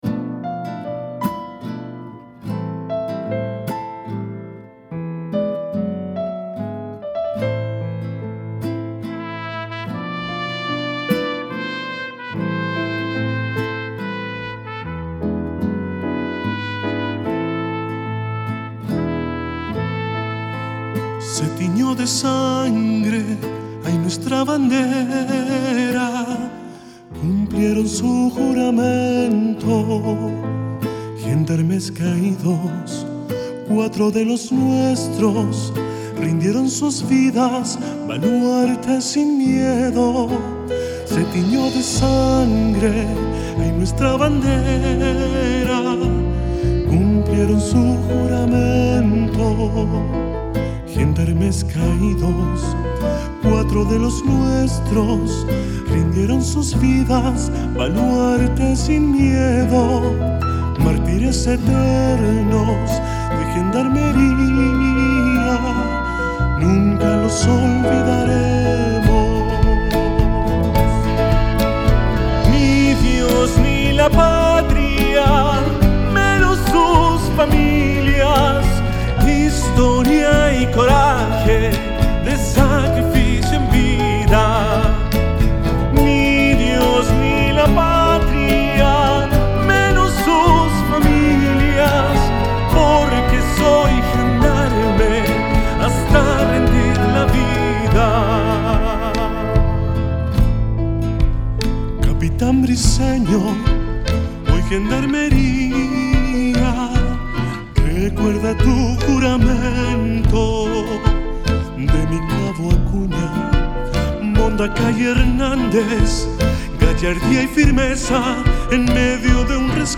Concurso folclÓrico 2023
con la tonada “Mártires Eternos”.